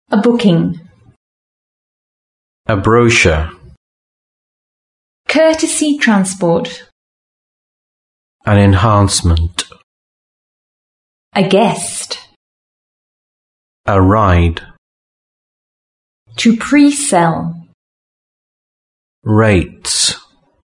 Now listen to how the words are pronounced.